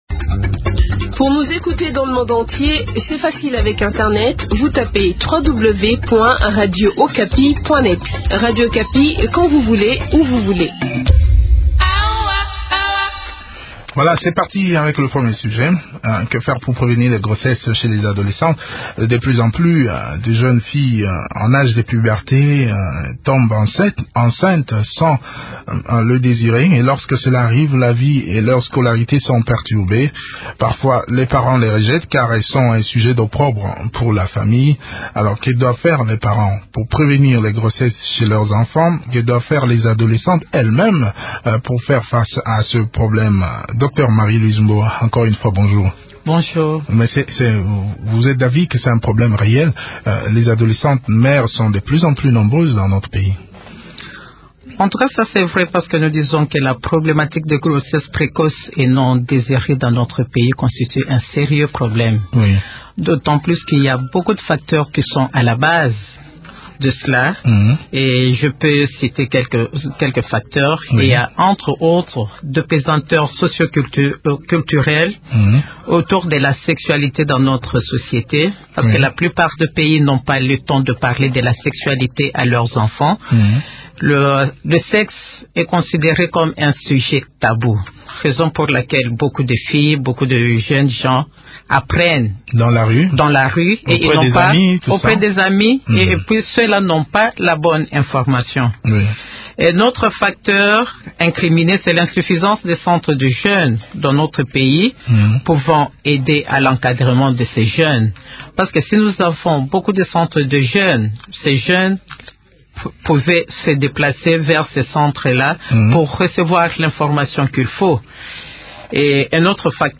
s’entretient sur les méthodes de prévention de grossesse